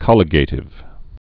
(kŏlĭ-gātĭv)